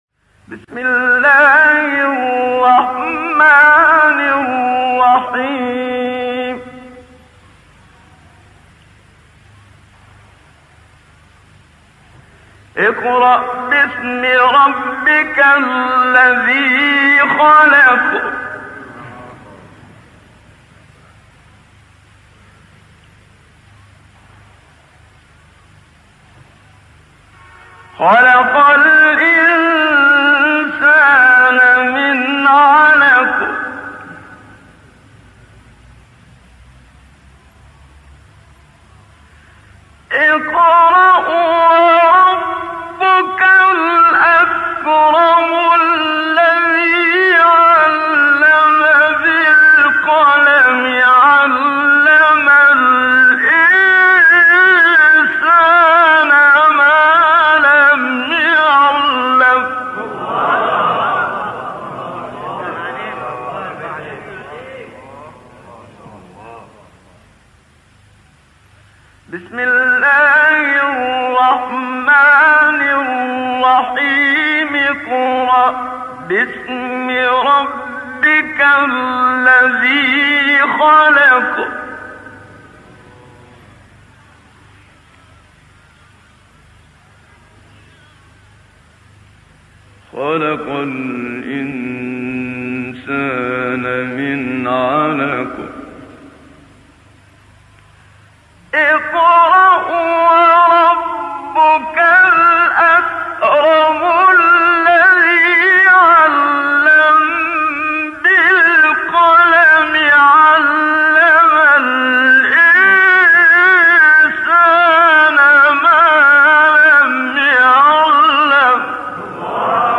سوره : علق آیه: 1-5 استاد : محمد صدیق منشاوی مقام : رست قبلی بعدی